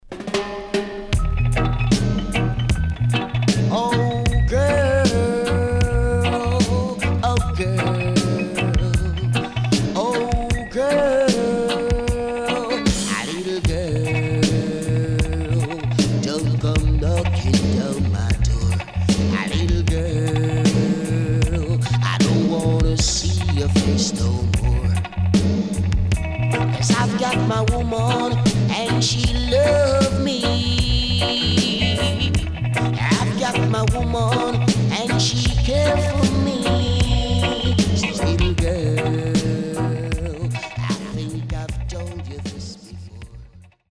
Rare.Killer Vocal.W-Side Good.Good Condition
SIDE A:少しチリノイズ入りますが良好です。